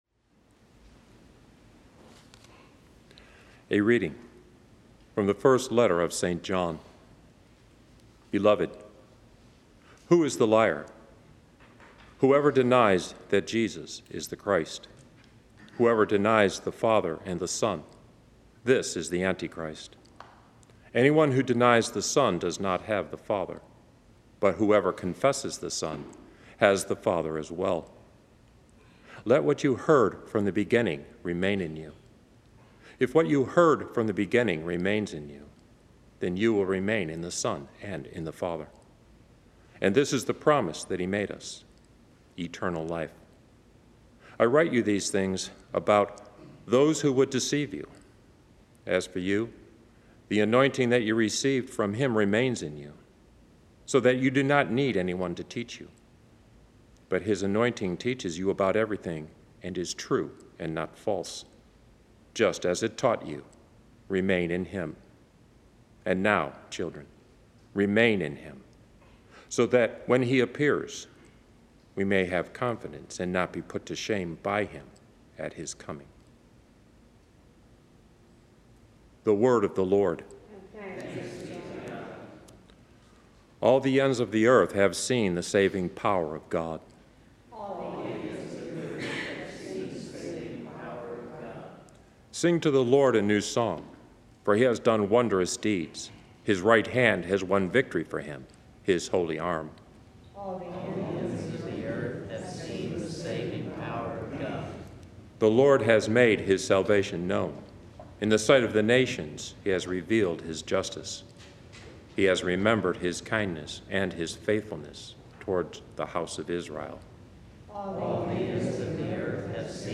Readings, Homily and Daily Mass
From Our Lady of the Angels Chapel on the EWTN campus in Irondale, Alabama.